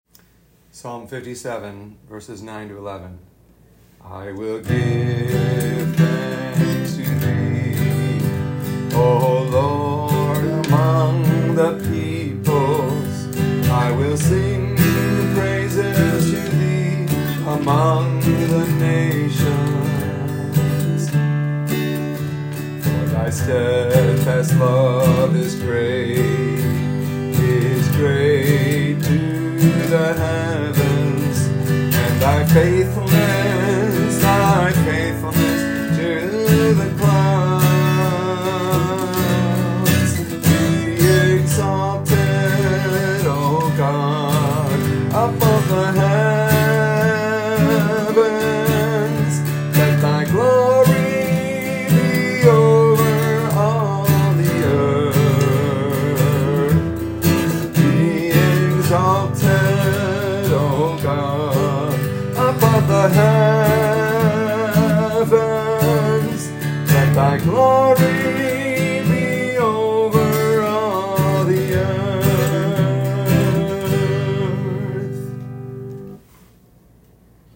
Transpose from G
First note=E